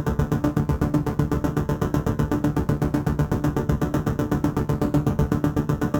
Index of /musicradar/dystopian-drone-samples/Tempo Loops/120bpm
DD_TempoDroneC_120-D.wav